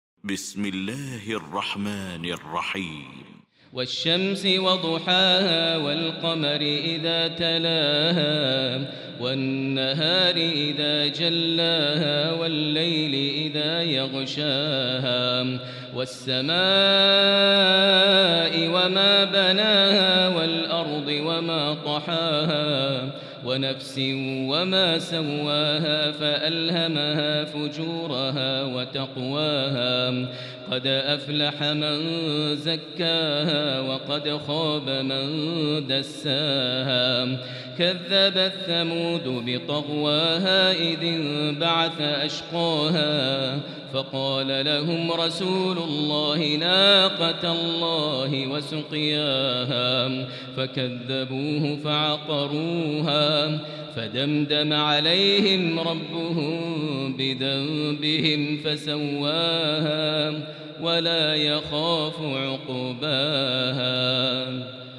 المكان: المسجد الحرام الشيخ: فضيلة الشيخ ماهر المعيقلي فضيلة الشيخ ماهر المعيقلي الشمس